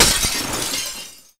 shatter.wav